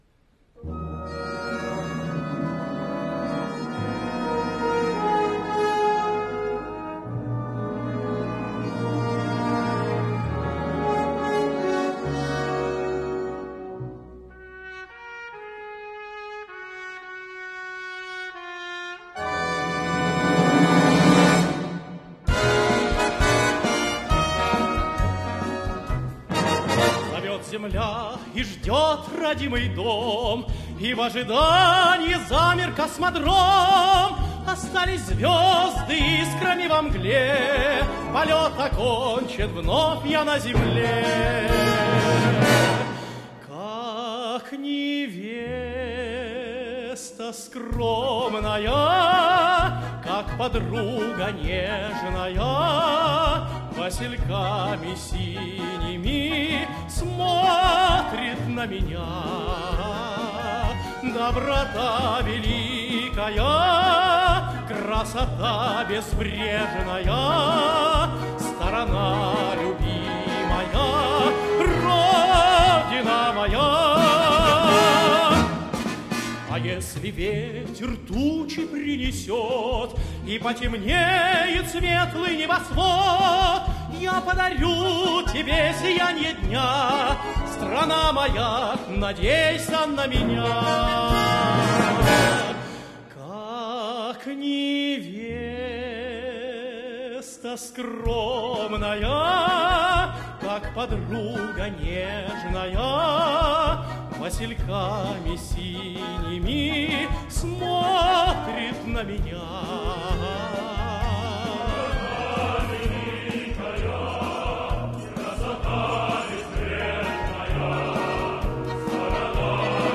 С концерта в КЗЧ 22 апреля 2024 года